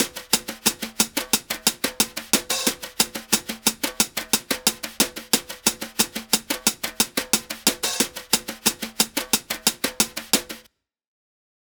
Unison Jazz - 7 - 90bpm - Tops.wav